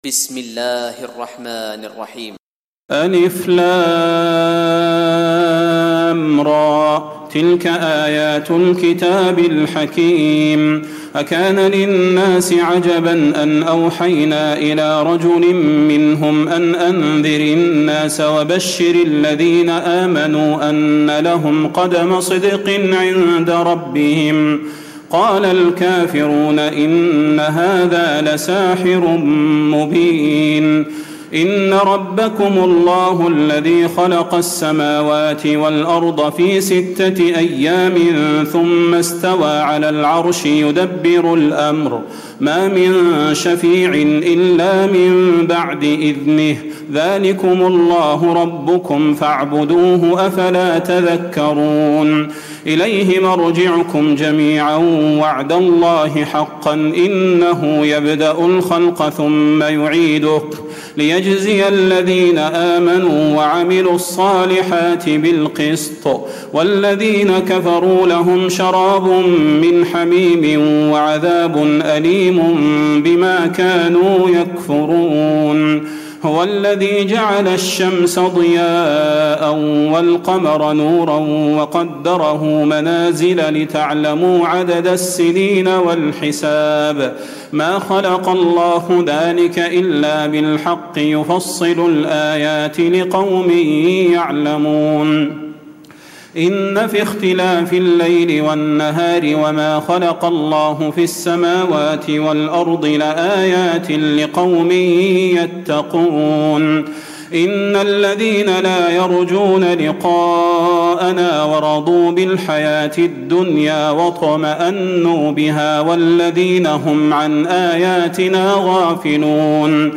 تراويح الليلة الحادية عشر رمضان 1436هـ من سورة يونس (1-92) Taraweeh 11 st night Ramadan 1436H from Surah Yunus > تراويح الحرم النبوي عام 1436 🕌 > التراويح - تلاوات الحرمين